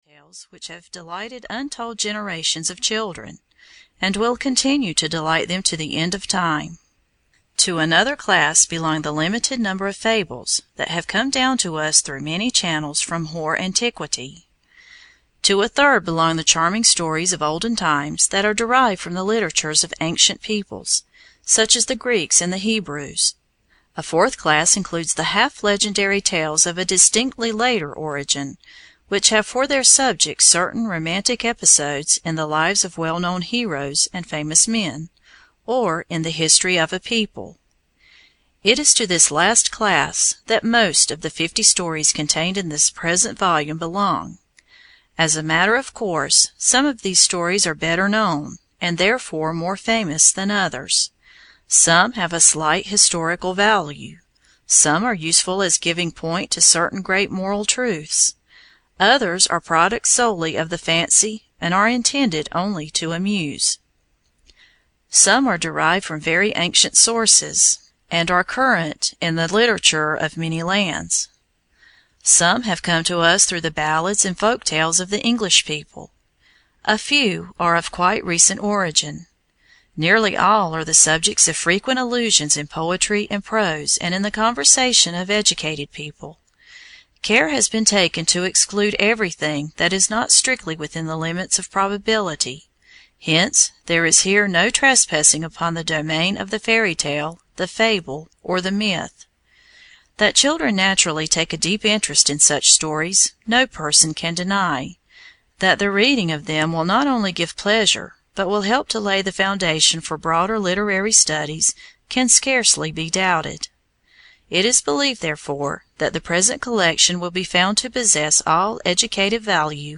Fifty Famous Stories Retold (EN) audiokniha
Ukázka z knihy